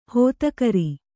♪ hōtakari